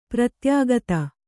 ♪ pratyāgata